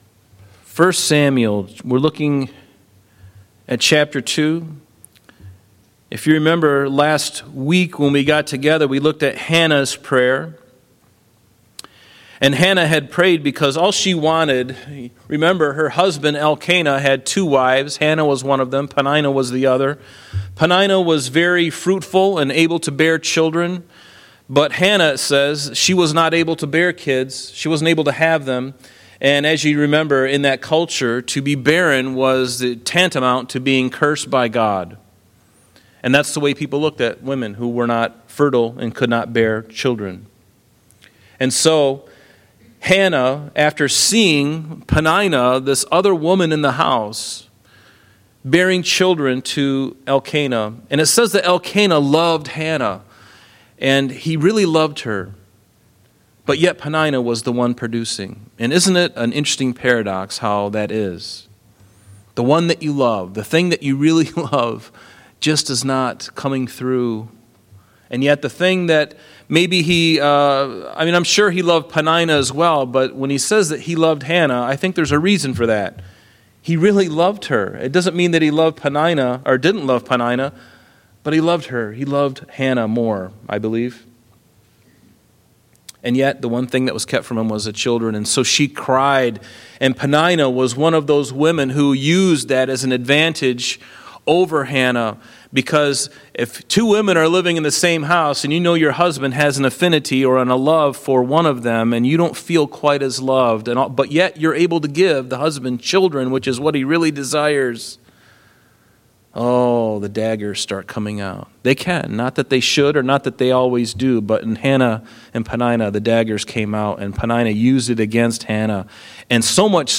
1 Samuel 2:12-17 – Thursday Night Bible Study